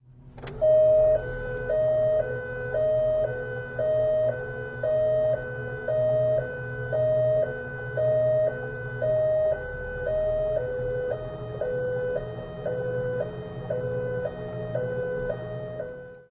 Police Siren: Interior Perspective with Various Types.